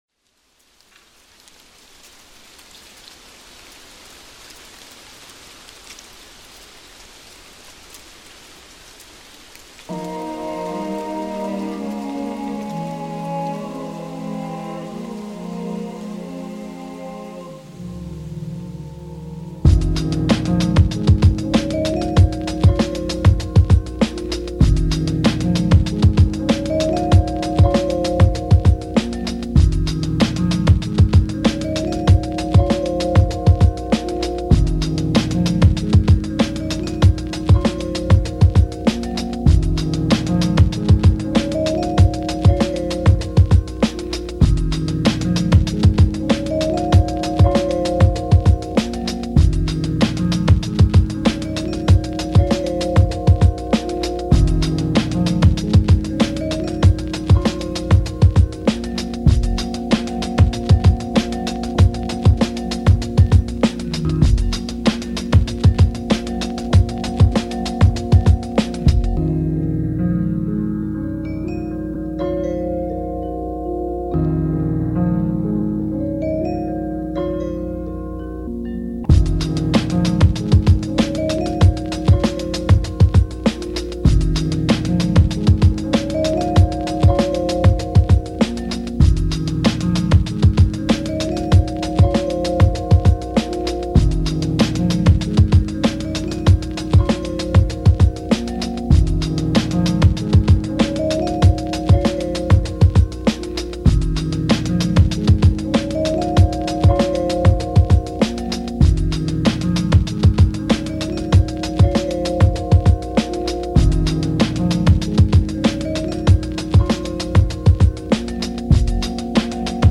Fall-In(strumentals) 2020